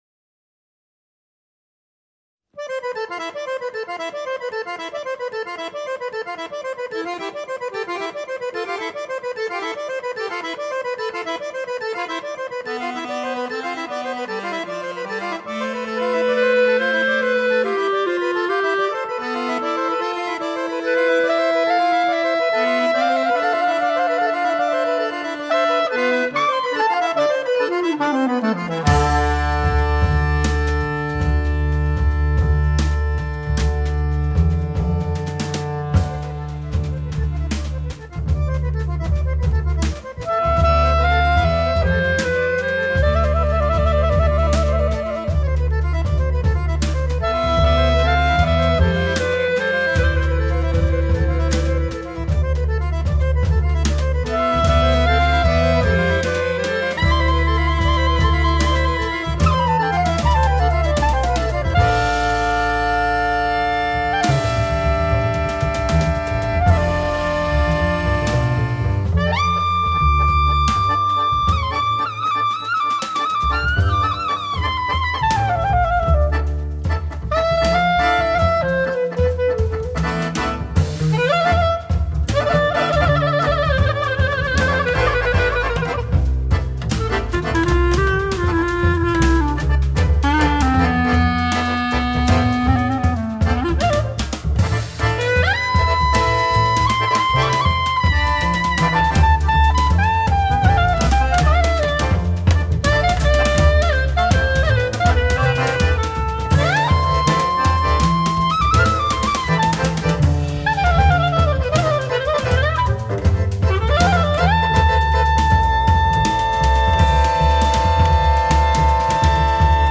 Saxofone, Klarinetten
Akkordeon
Schlagwerk, Percussion
aktueller Jazz mit innovativem Sound